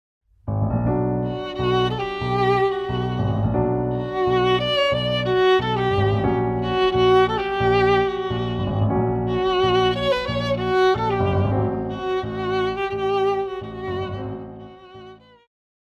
古典,流行
钢琴
演奏曲
世界音乐
仅伴奏
没有主奏
没有节拍器